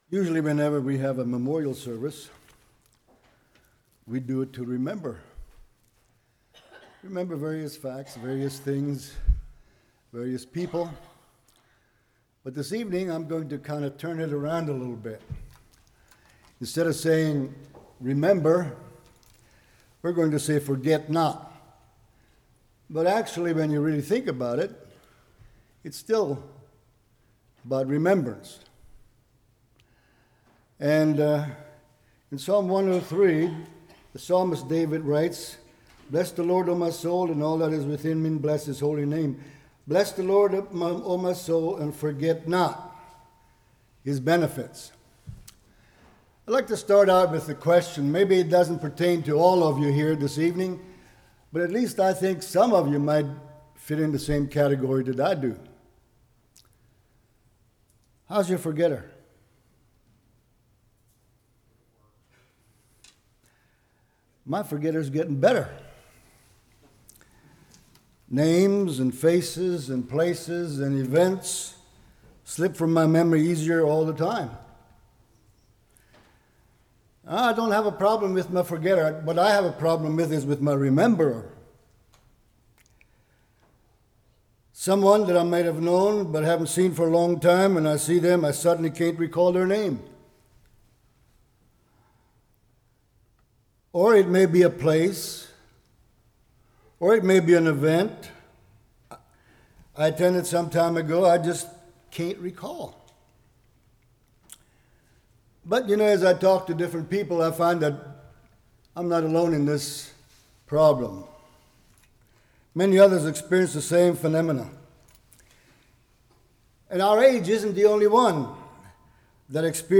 Psalms 103:1-12 Service Type: Evening Forgiveth iniquities healeth diseases Redeem life from destruction « A Foundation for Living Which Yardstick am I Using?